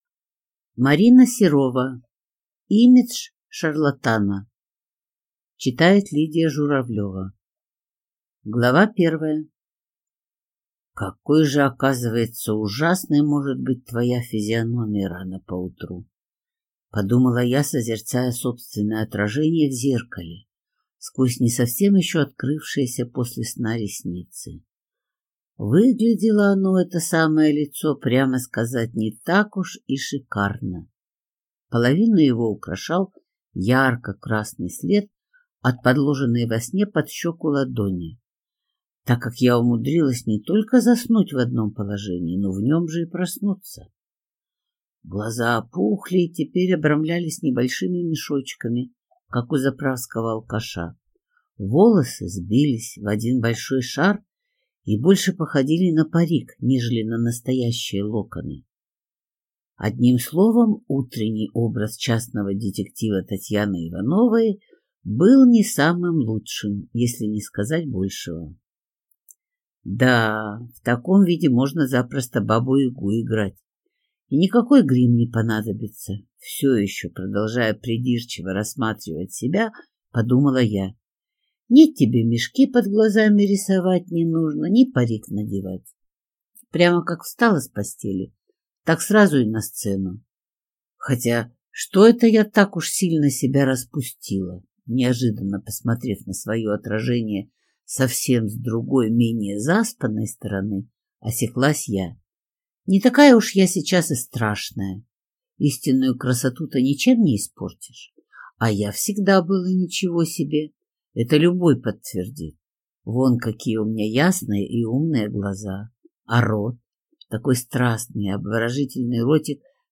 Аудиокнига Имидж шарлатана | Библиотека аудиокниг
Прослушать и бесплатно скачать фрагмент аудиокниги